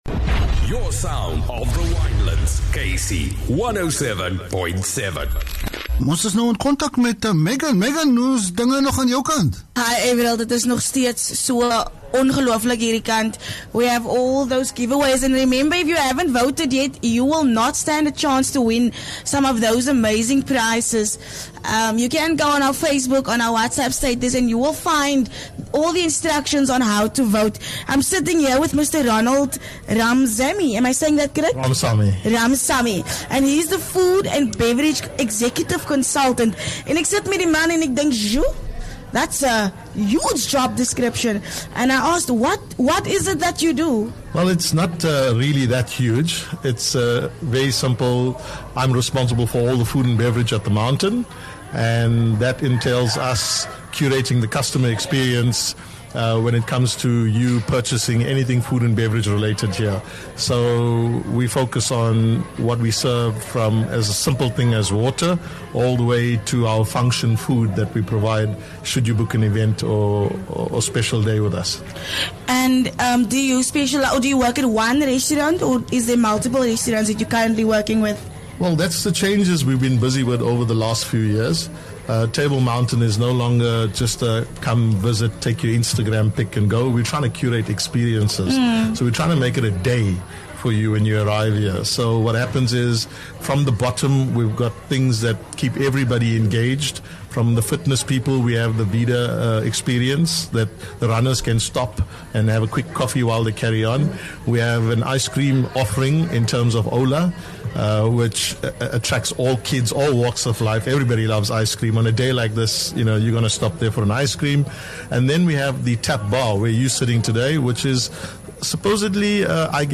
4 Oct KC107.7 LIVE from Table Mountain- 4 Oct 24
Interview